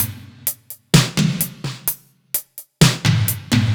Index of /musicradar/french-house-chillout-samples/128bpm/Beats
FHC_BeatC_128-02_NoKick.wav